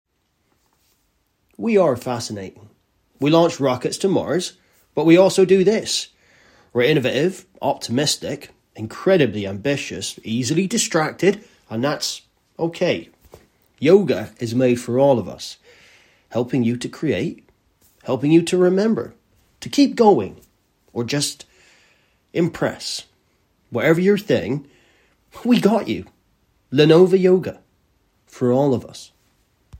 RP/English Neutral, Deep, Gravitas, Musical, Natural